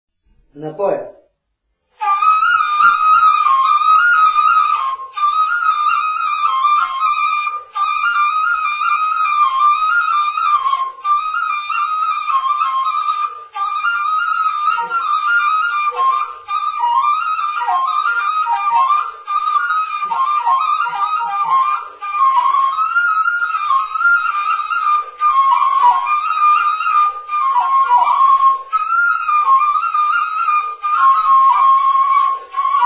музикална класификация Инструментал
тематика Хороводна (инструментал)
размер Две четвърти
фактура Едногласна
начин на изпълнение Солово изпълнение на кавал
битова функция На хоро
фолклорна област Югоизточна България (Източна Тракия с Подбалкана и Средна гора)
място на записа Меричлери
начин на записване Магнетофонна лента